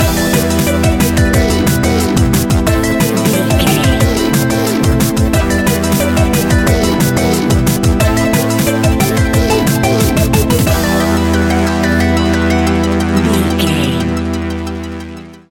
Aeolian/Minor
Fast
hypnotic
industrial
frantic
aggressive
dark
drum machine
synthesiser
electronic
sub bass
synth leads
synth bass